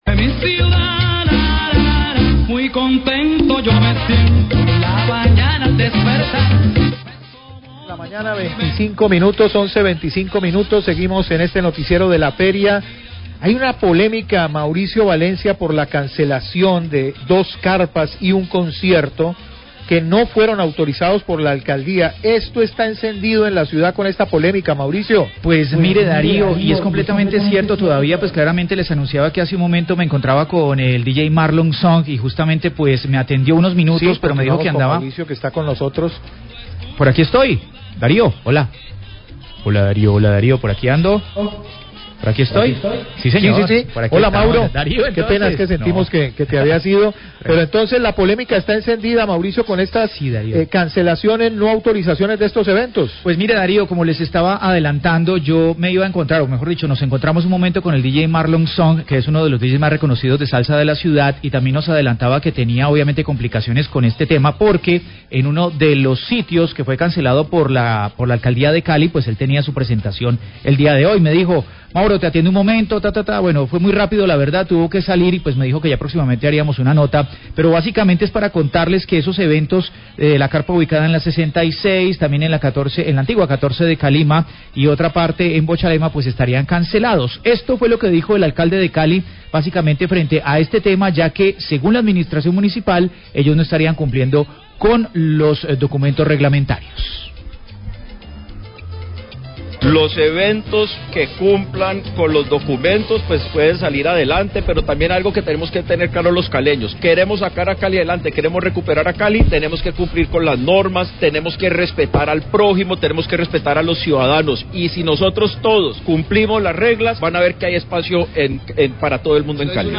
Alcalde Alejandro Eder habla sobre la cancelación de dos carpas en la Feria de Cali
Radio